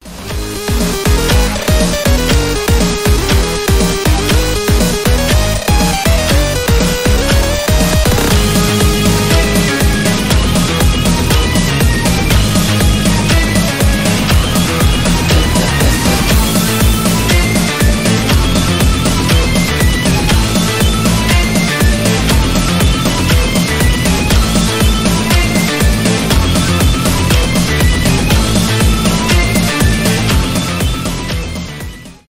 электронные
громкие